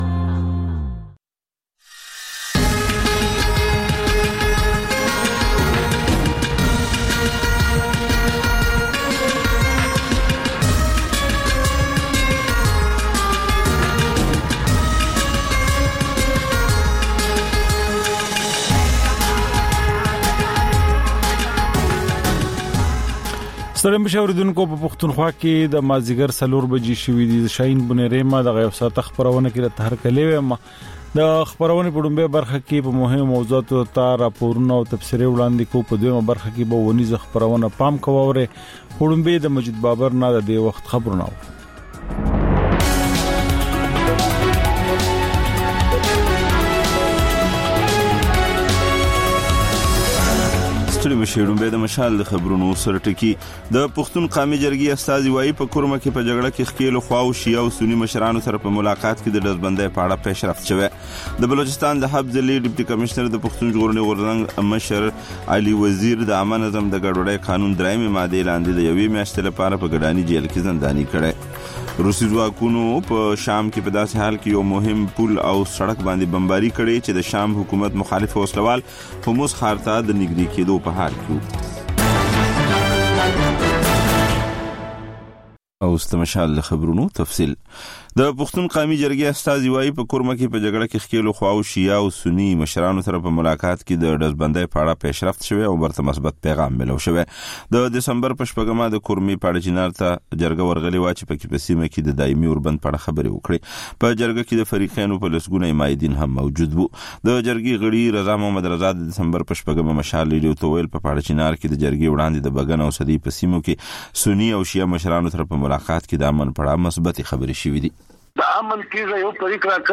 د مشال راډیو مازیګرنۍ خپرونه. د خپرونې پیل له خبرونو کېږي، ورسره اوونیزه خپرونه/خپرونې هم خپرېږي.